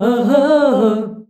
AHAAH D.wav